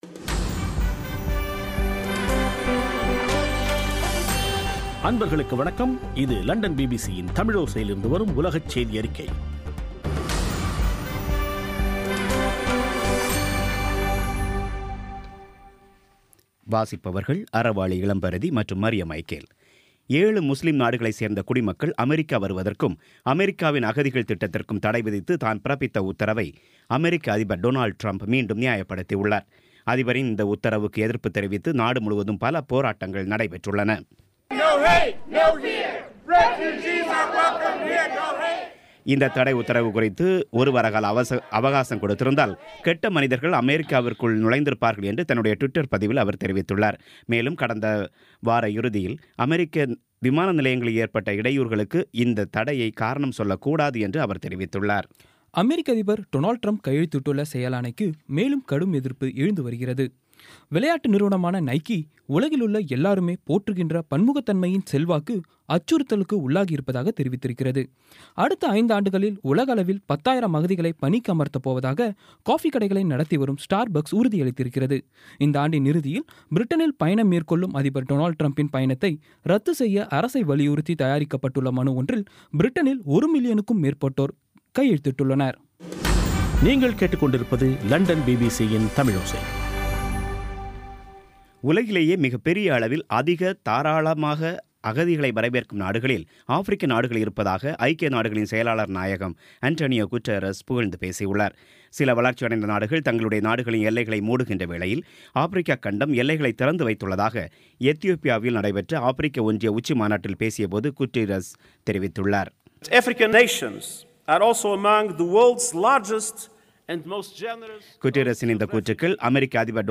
பிபிசி தமிழோசை செய்தியறிக்கை (30/01/2017)